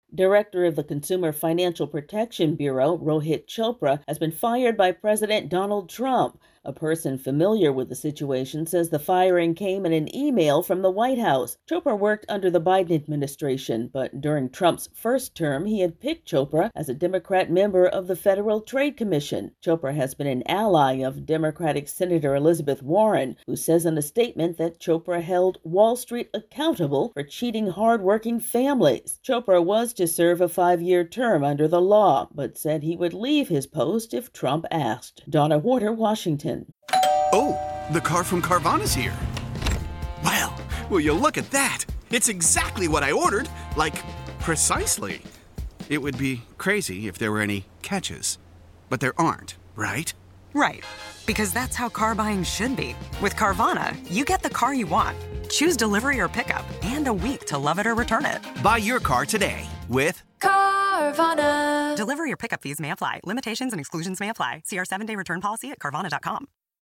President Donald Trump has fired the director of the Consumer Protection Bureau. AP correspondent